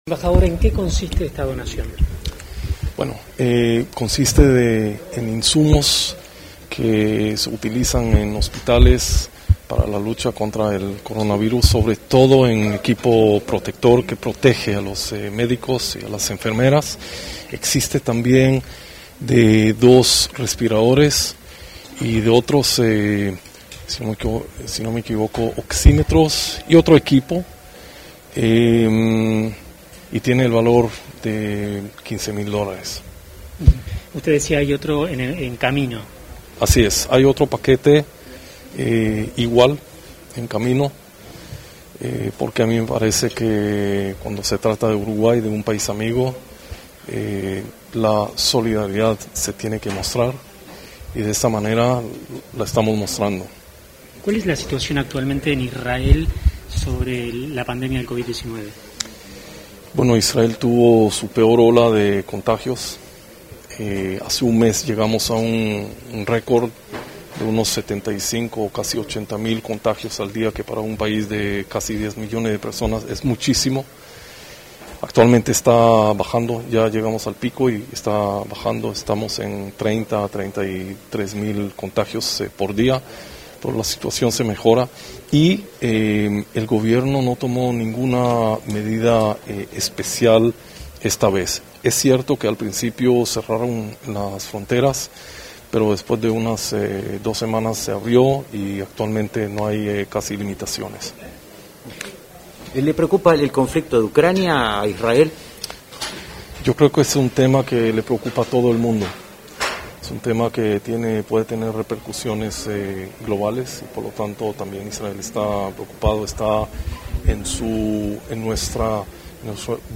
Declaraciones a la prensa del embajador de Israel ante Uruguay, Yoed Magen
Tras el acto, realizado este 14 de febrero, el diplomático israelí efectuó declaraciones a la prensa.